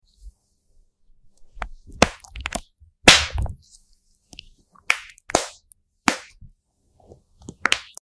Honey Book ASMR ✨ | sound effects free download